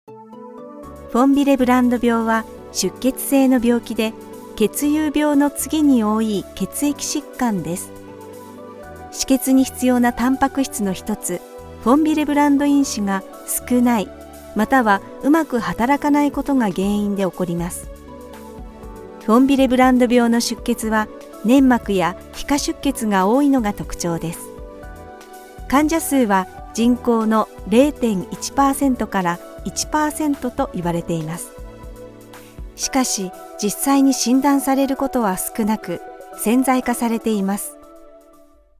Commercial, Natural, Reliable, Warm, Corporate
Explainer
personable, persuasive, versatile, warm and authentic